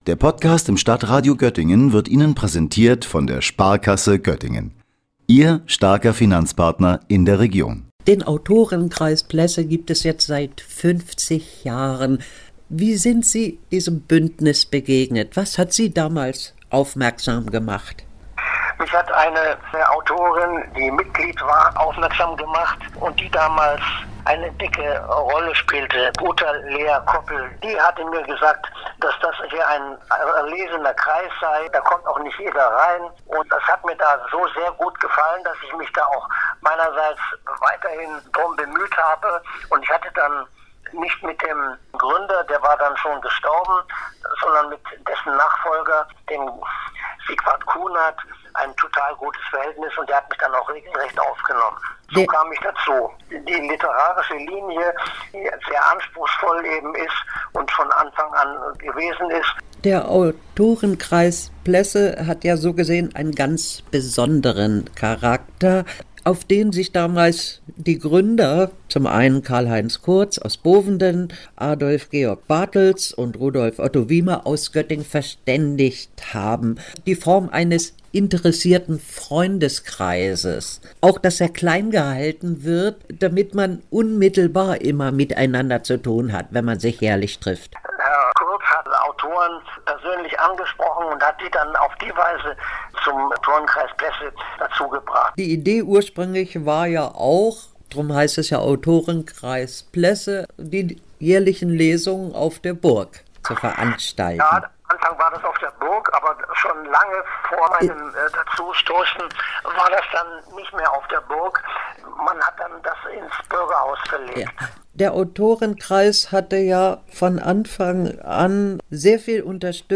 Beiträge > 50 Jahre Autorenkreis Plesse – Gespräch